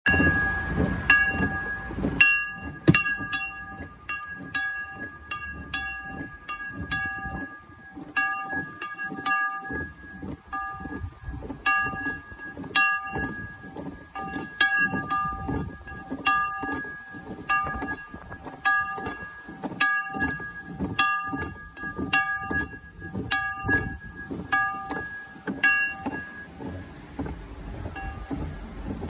Die Glocke selber ist wahrscheinlich aus Bronze, jedenfalls nicht magnetisch, der Klöppel aus geschmiedetem Eisen, wahrscheinlich noch original erhalten.
Eine Klangprobe der Glocke finden Sie
glocke-rochuskapelle-luelsdorf.mp3